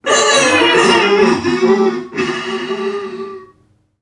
NPC_Creatures_Vocalisations_Robothead [61].wav